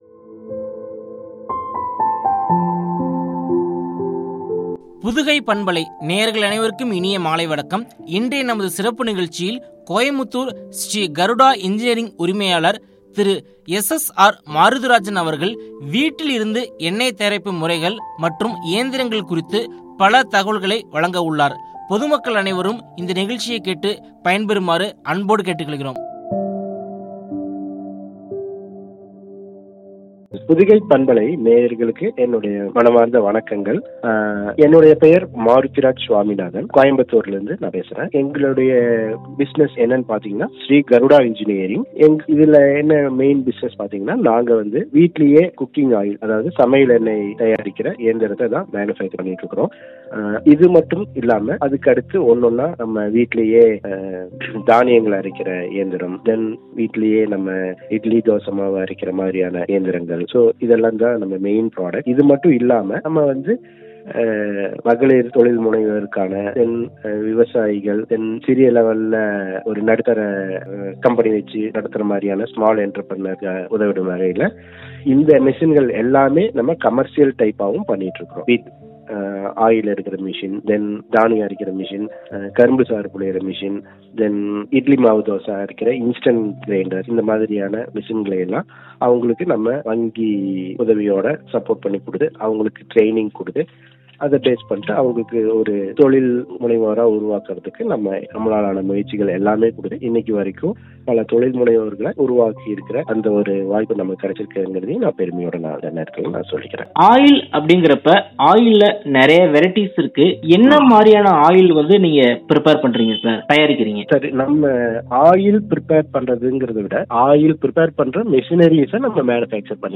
” குறித்த உரையாடல்.